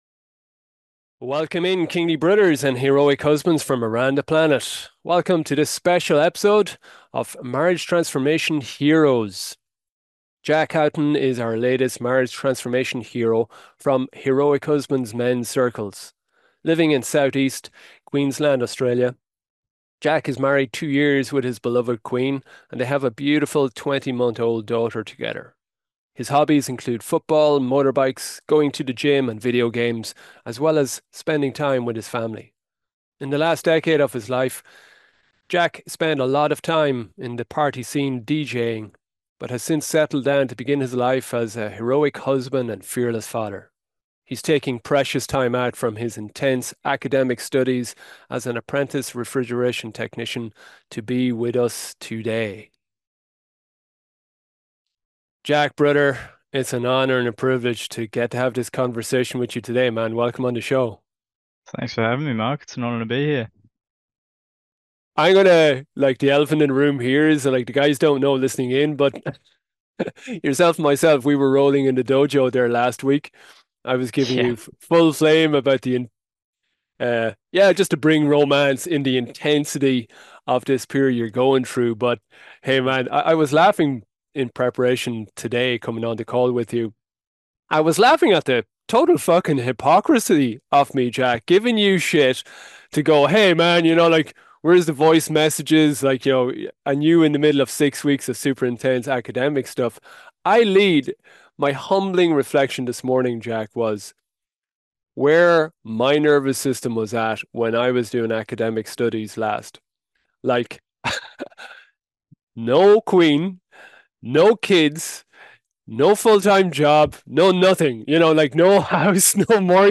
Enjoy a humorous and emotional rollercoaster conversation that will have you exit with radical hope in your capacity to transform your marriage too!